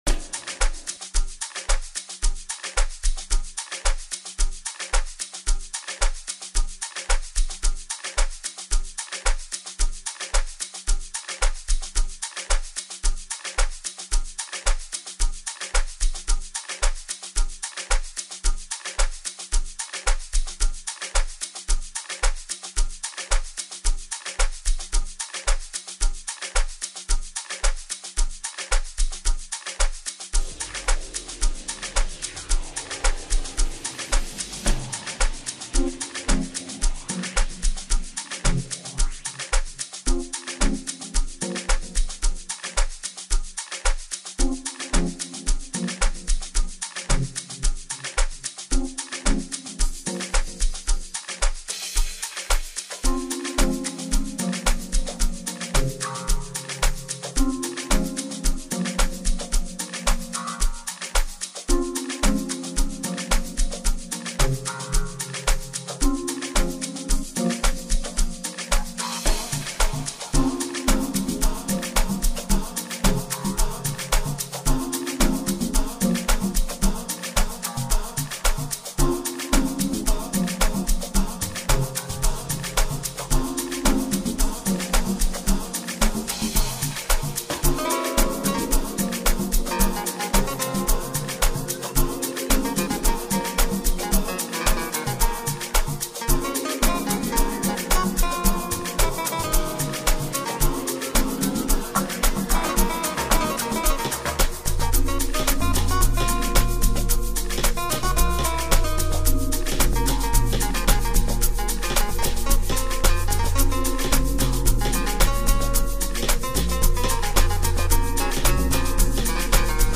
Amapiano
this one combines many different piano elements